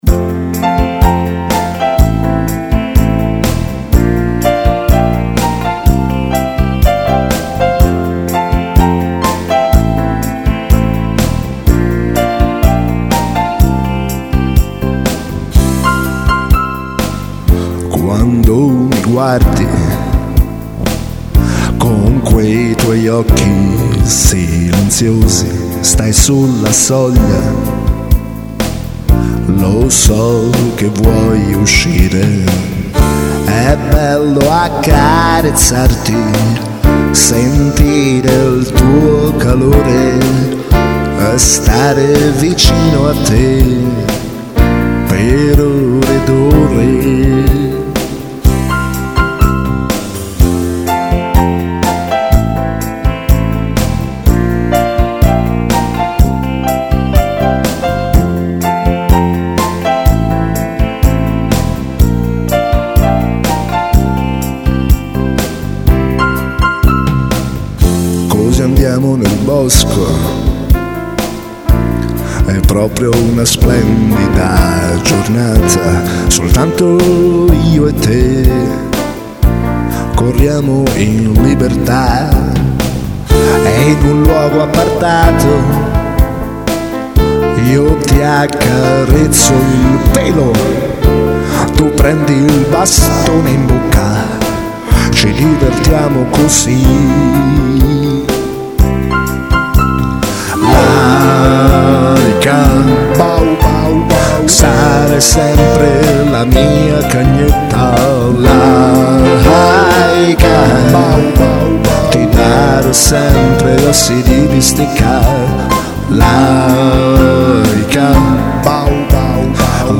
Questa dolce canzone d'amore la scrissi ben 21 anni fa, ma ultimamente mi è stato chiesto di registrarla... eccola qui fresca fresca